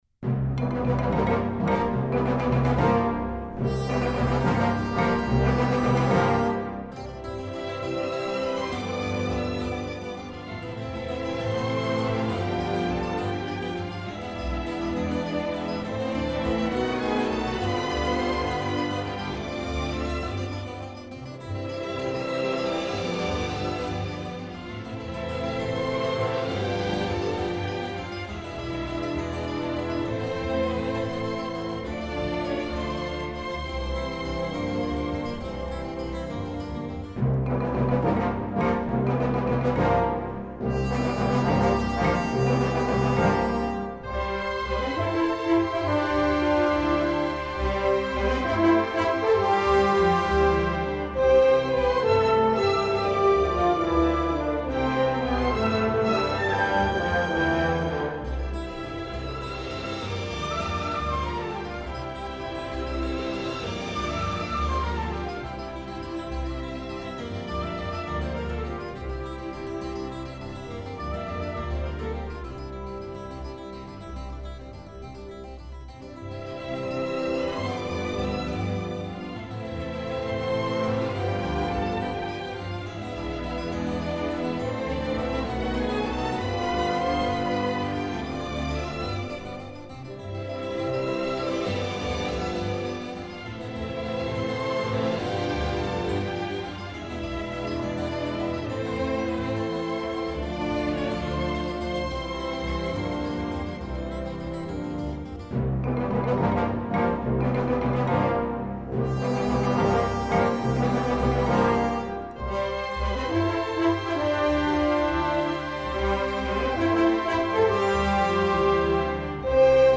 音乐类别：电影音乐
清新冰凉的夏日情怀